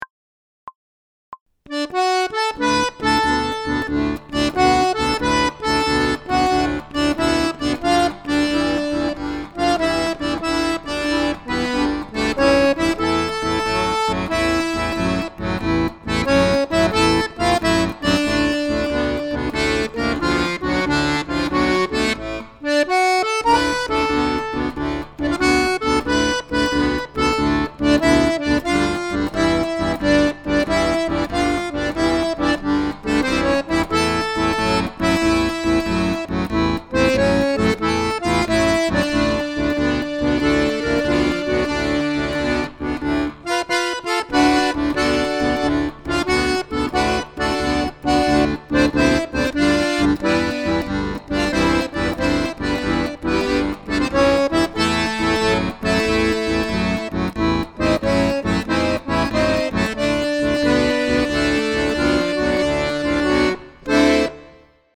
DIGITAL SHEET MUSIC - ACCORDION SOLO